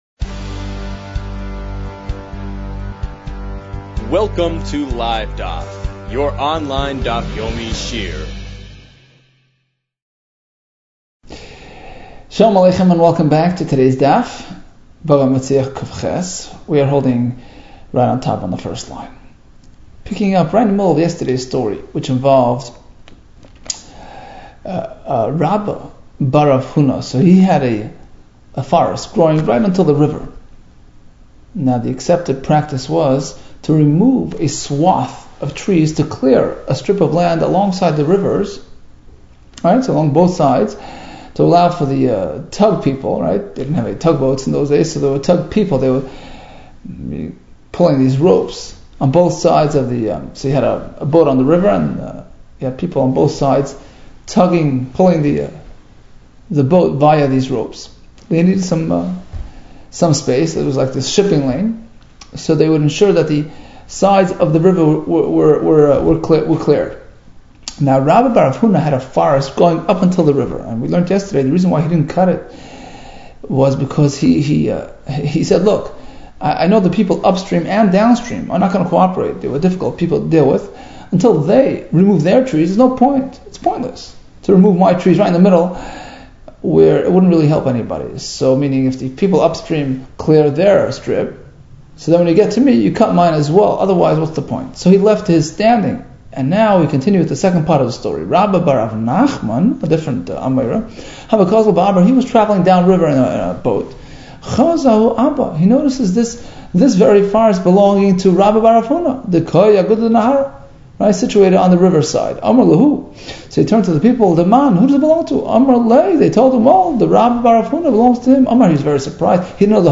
Bava Metzia 107 - בבא מציעא קז | Daf Yomi Online Shiur | Livedaf